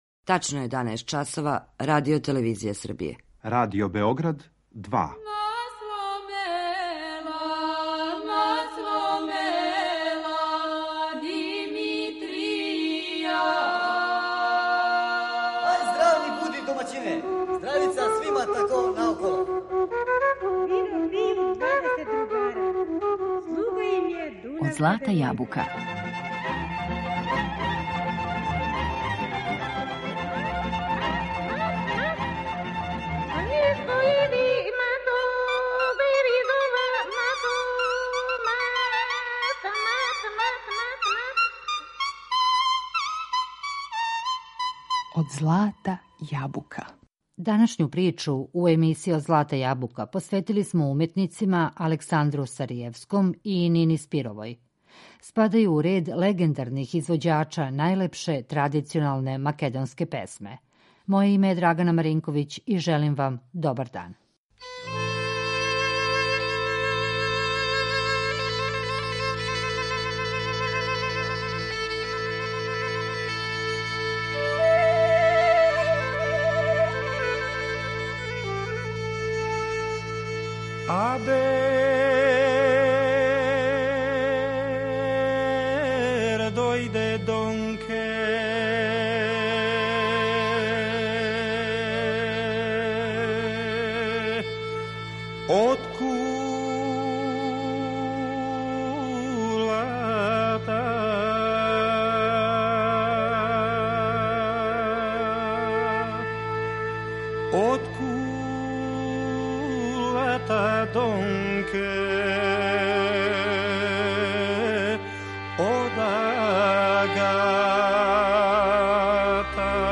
Македонска народна песма